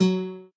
minecraft / sounds / note / guitar.ogg
guitar.ogg